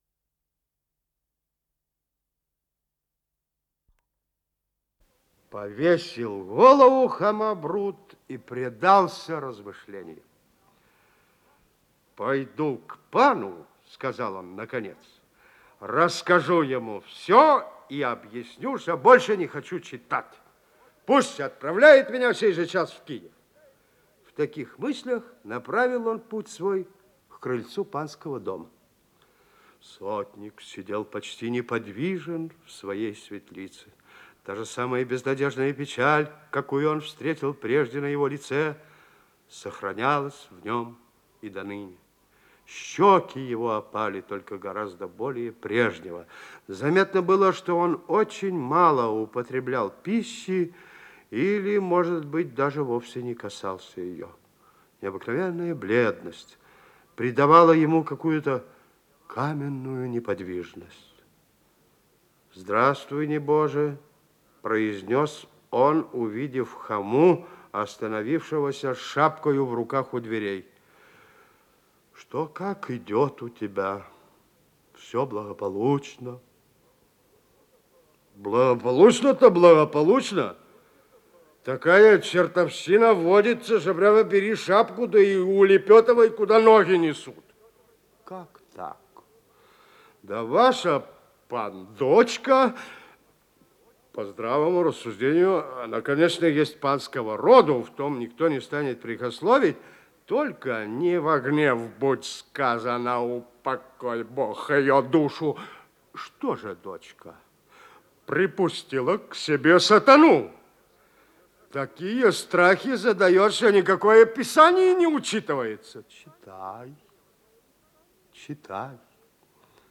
Исполнитель: Борис Бабочкин
Повесть, 4 часть